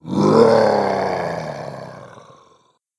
beast_roar_long1.ogg